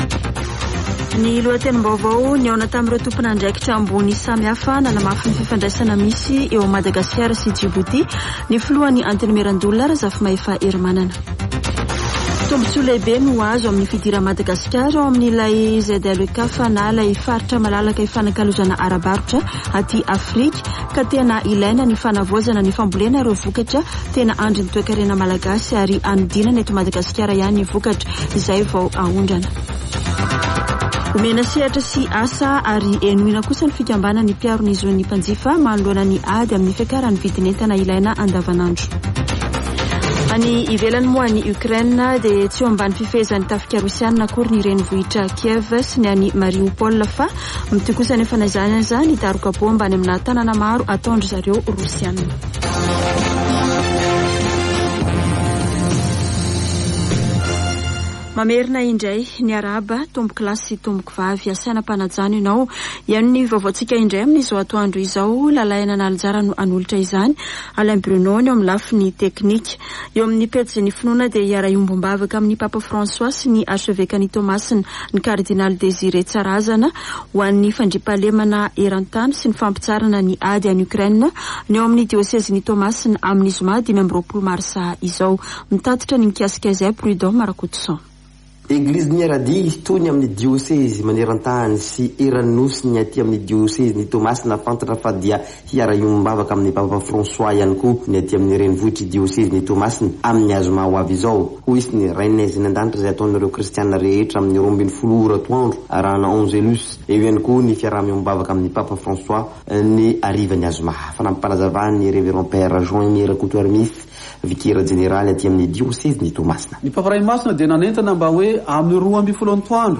[Vaovao antoandro] Alarobia 23 marsa 2022